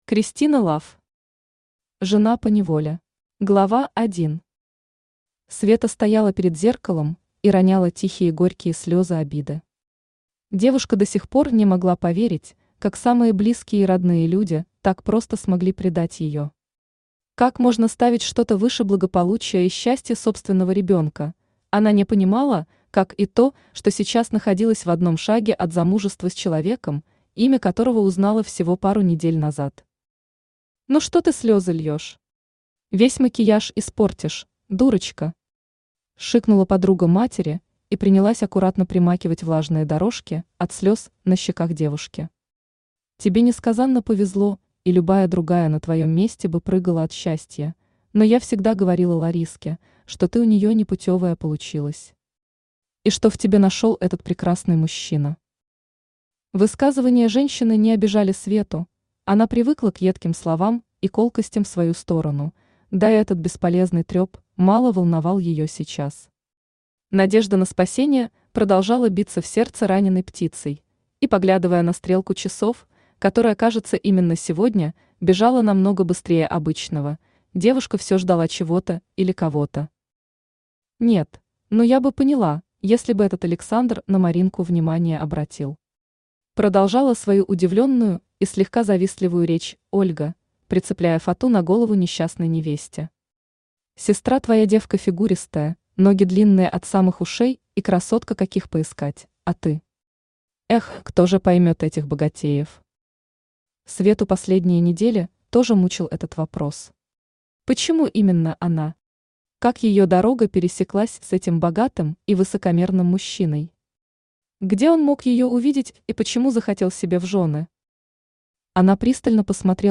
Аудиокнига Жена поневоле | Библиотека аудиокниг
Aудиокнига Жена поневоле Автор Кристина Лафф Читает аудиокнигу Авточтец ЛитРес.